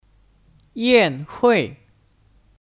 宴会 Yànhuì (Kata benda): Perjamuan